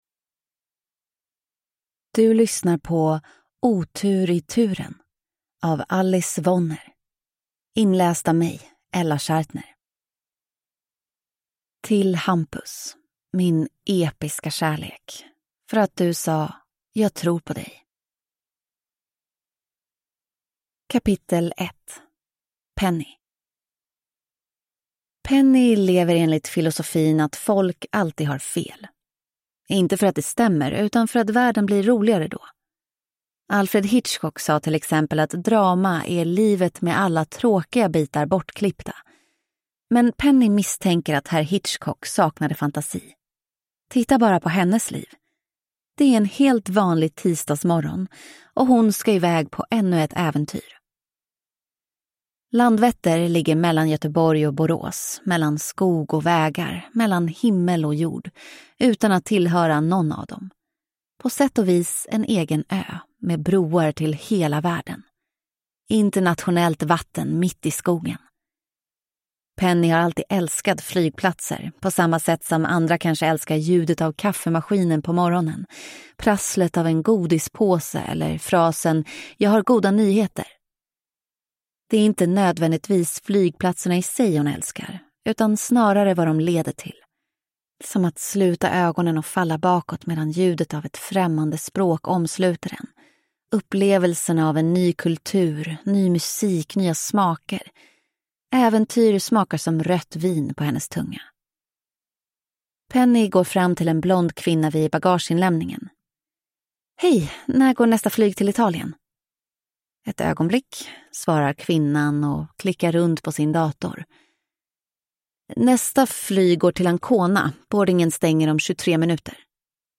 Otur i turen – Ljudbok